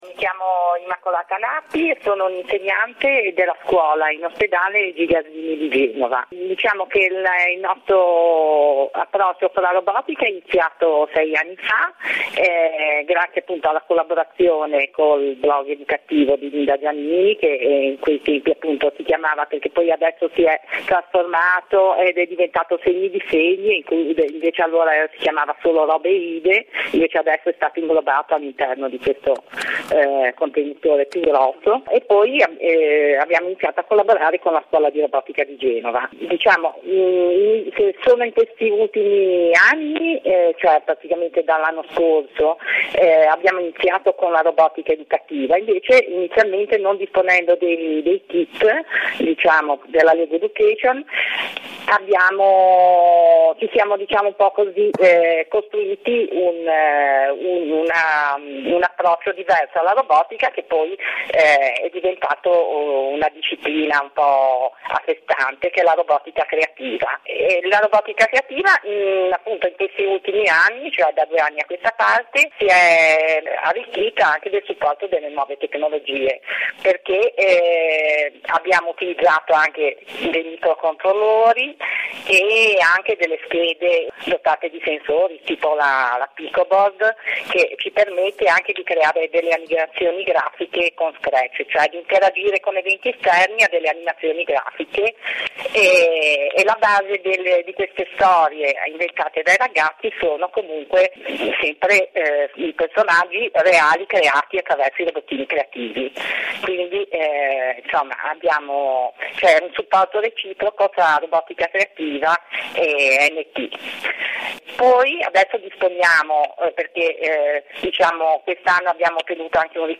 Nell’intervista telefonica